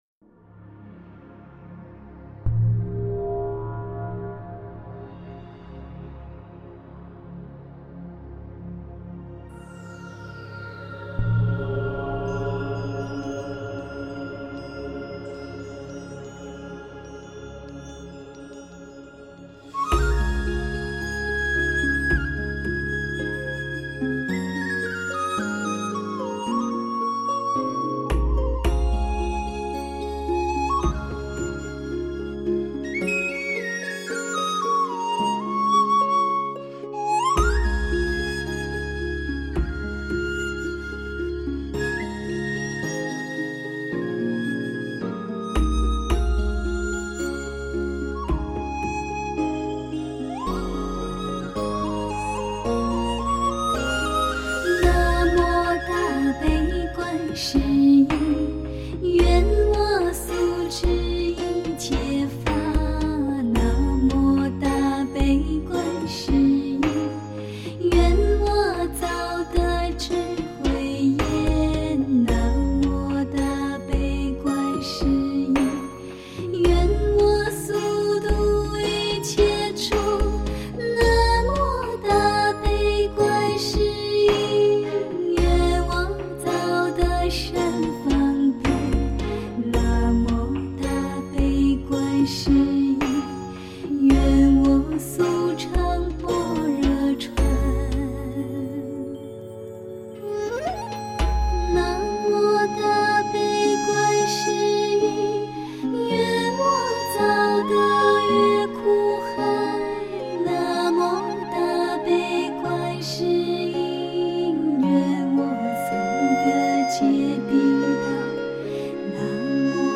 天籁梵音
古琴古韵古寺院，千年传承世世传唱，